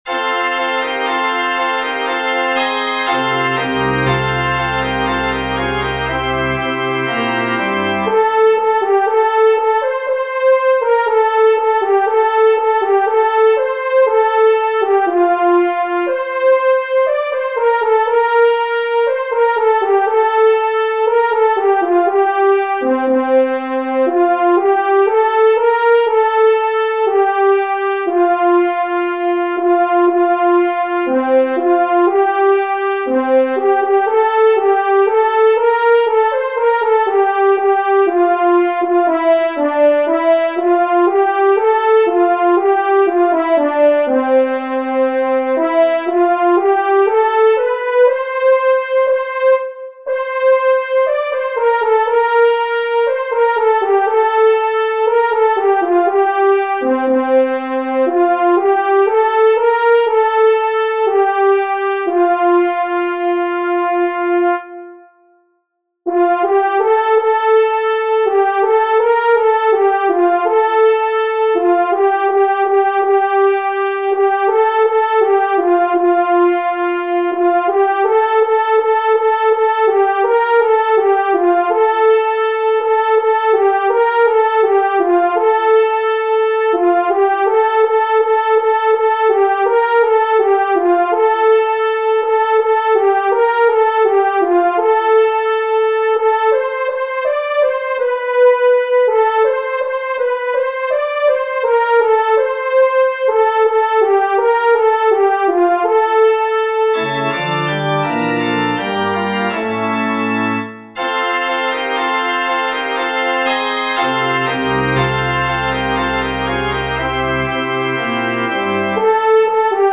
For the cantors:
(Warning: special lower notes beginning at Letter E (1:48). Not the same as the soprano part.)
a_new_christmas_gloria-cantor.mp3